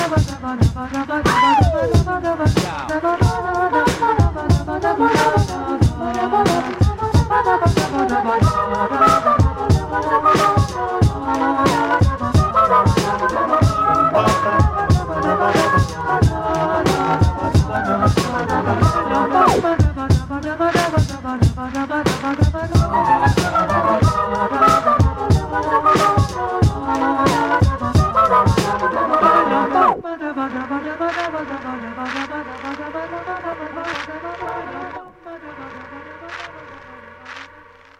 chilled and soulful side
He’s been teaching himself to play keys
lush vocals